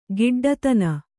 ♪ giḍḍatana